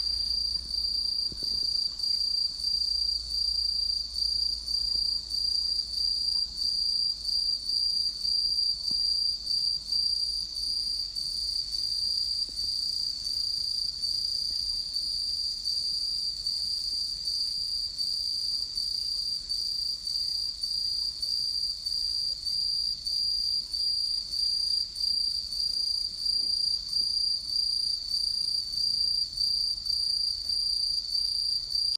こちらは、宿泊したお部屋の外で鳴いていた秋の虫たちの音色がとっても美しかったので、ついつい、iPhoneで録音したものになります。